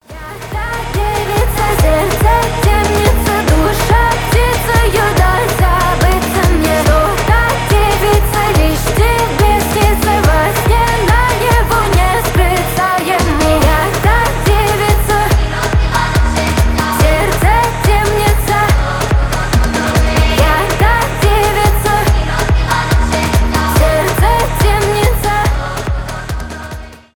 2025 » Новинки » Русские » Поп Скачать припев